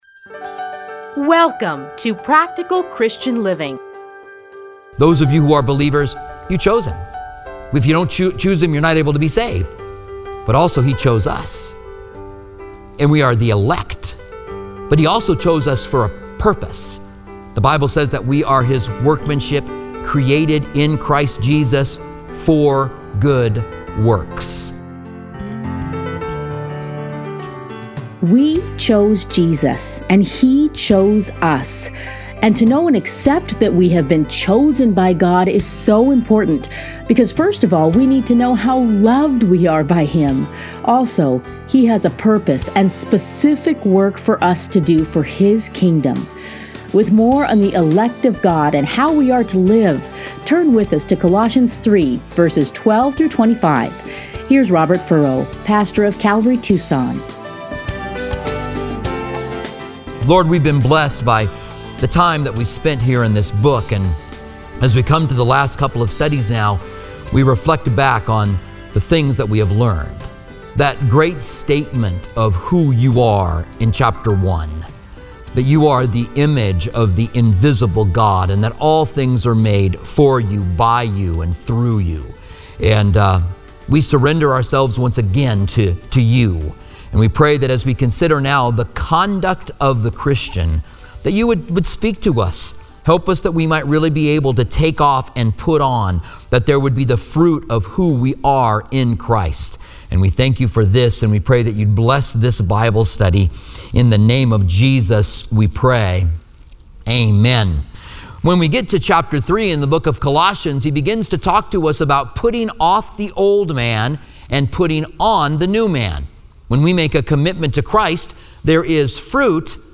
Listen here to a teaching from Colossians.